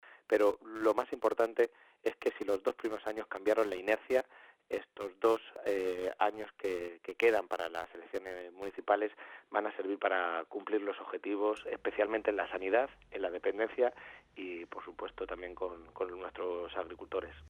Toledo, 15 de enero de 2018.- El secretario de Organización del PSOE de Castilla-La Mancha, Sergio Gutiérrez, en una entrevista concedida a la Cadena Cope en nuestra región, ha asegurado que lo que está haciendo el gobierno de Rajoy con Castilla-La Mancha solo tiene un nombre: “Chantaje y discriminación”.
Cortes de audio de la rueda de prensa